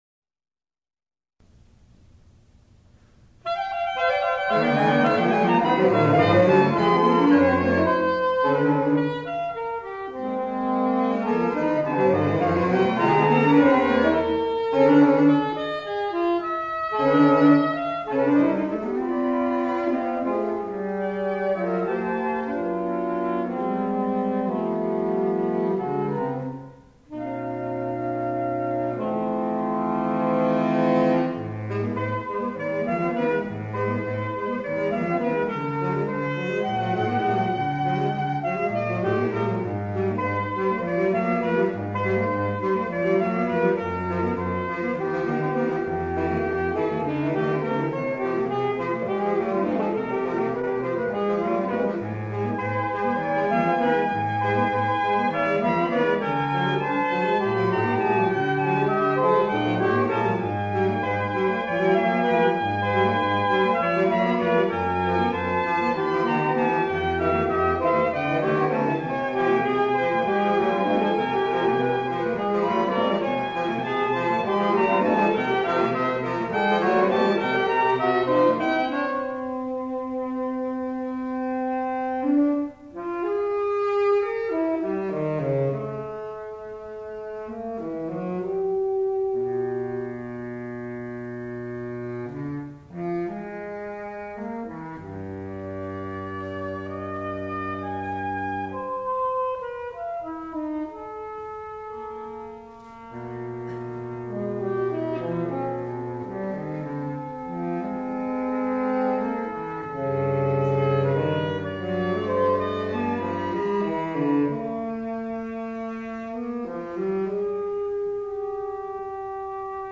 saxophone ensemble